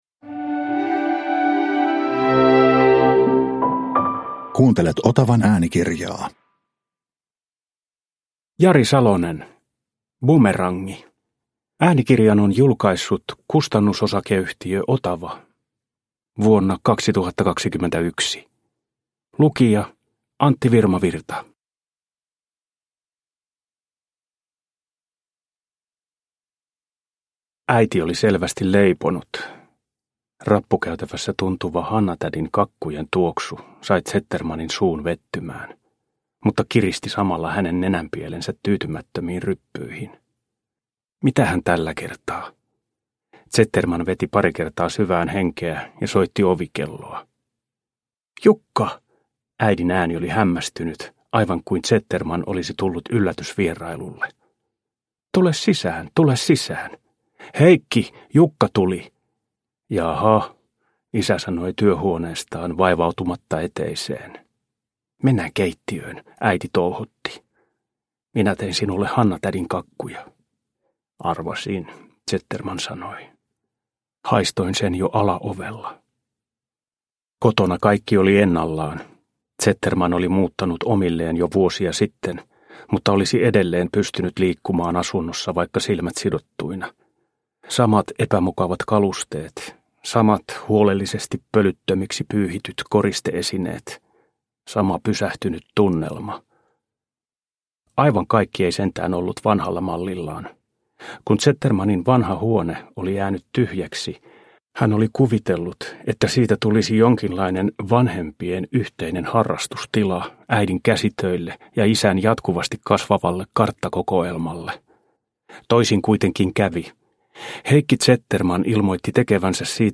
Bumerangi – Ljudbok – Laddas ner
Uppläsare: Antti Virmavirta